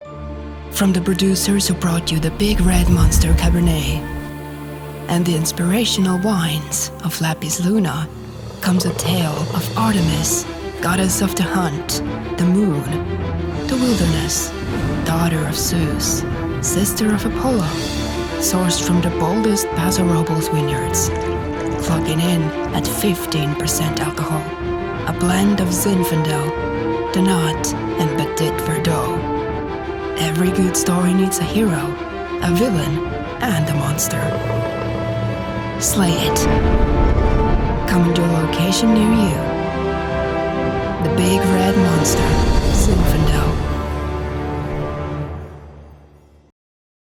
Soy locutora estonia nativa y trabajo tanto en estonio como en inglés, ¡con un ligero acento!
Mi acento es lo suficientemente suave como para ser fácilmente comprensible, además de ser adecuado para conectar con gente de todo el mundo.